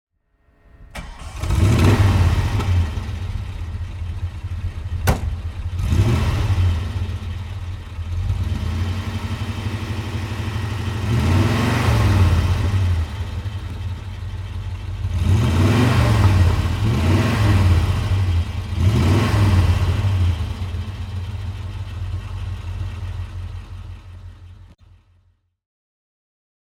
And you can also listen to the car!
Glas 3000 V8 Coupé (1968) - Starten und Leerlauf